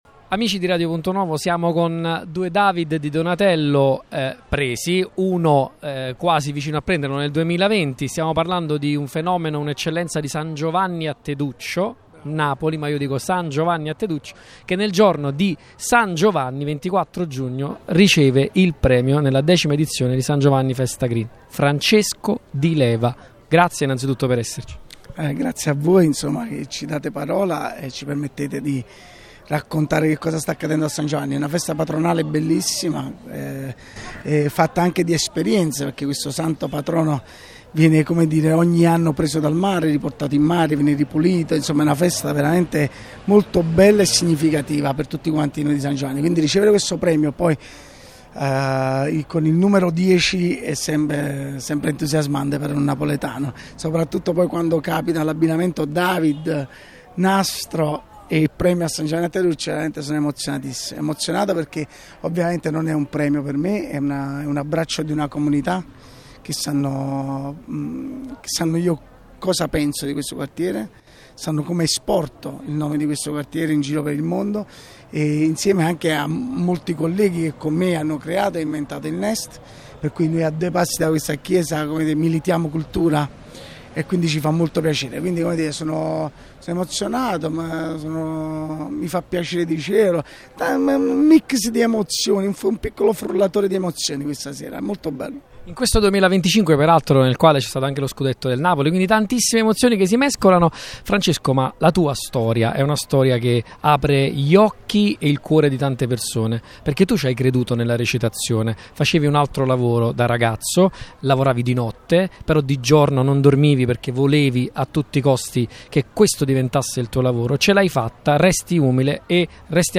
Francesco Di Leva, attore tra i più intensi del cinema italiano contemporaneo, è stato insignito del premio nell’ambito della decima edizione di San Giovanni Festa Green.
Accompagnato dall’entusiasmo del pubblico e dal calore del quartiere, Di Leva si è lasciato andare a una lunga intervista che è stata un mix di emozione, memoria e riflessione civile.
Francesco-Di-Leva-San-Giovanni-festa-Green.mp3